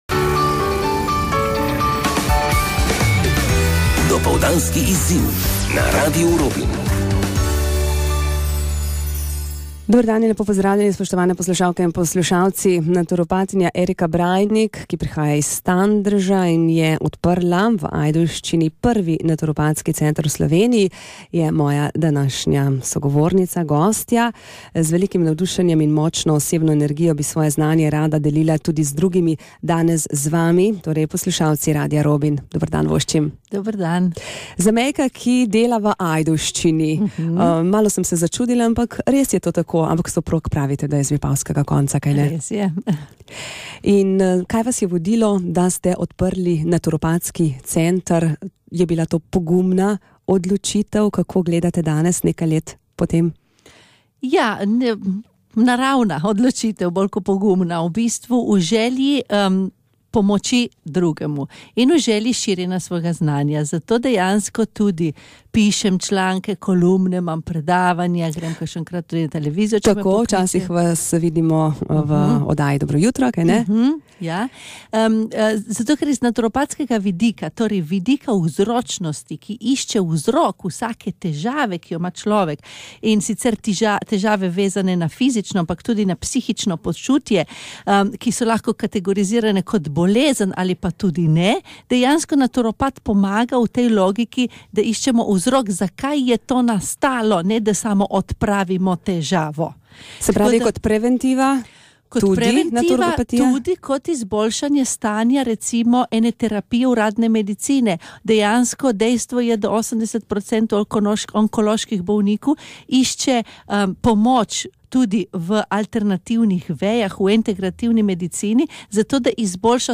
Intervju Radio Radio robin 16.12.2014